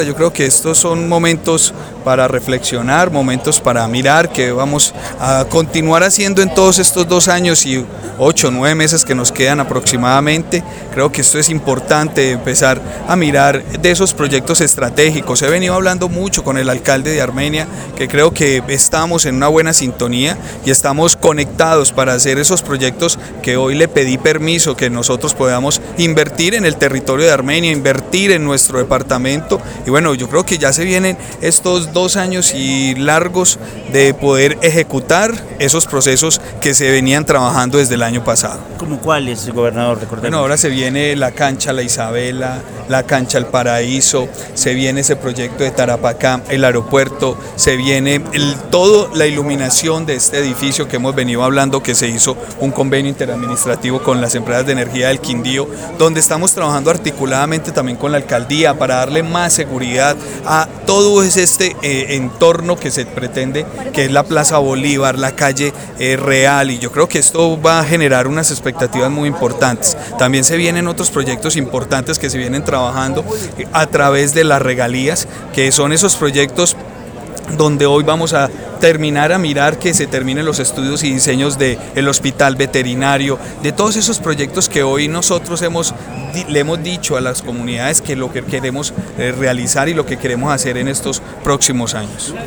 Audio Gobernador del Quindío, Juan Miguel Galvis Bedoya
audio-gobernador-contactos-alcalde-armenia.mp3